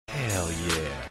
Hell Yeah Button - Instant Sound Effect Button | Myinstants
hell-yeah_5afnz5z.mp3